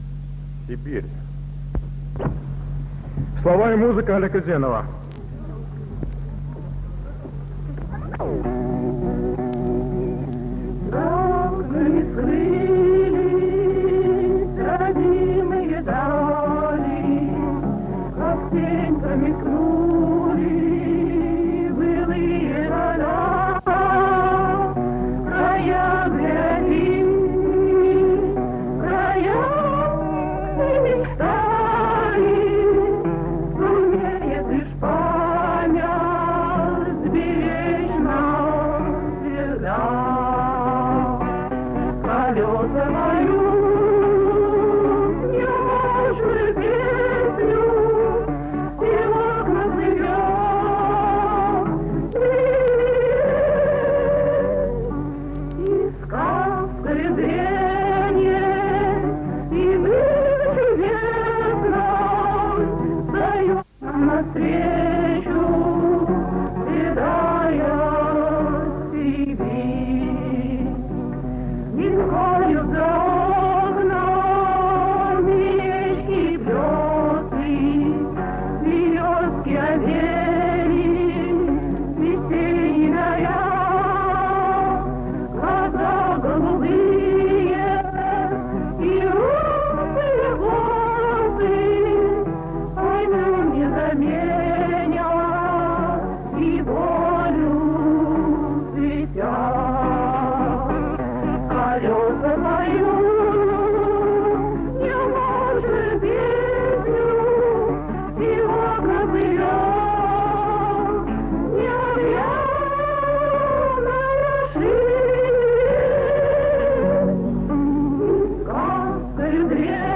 ВТОРОЙ МОСКОВСКИЙ ВЕЧЕР-КОНКУРС СТУДЕНЧЕСКОЙ ПЕСНИ
Мужской ансамбль МГРИ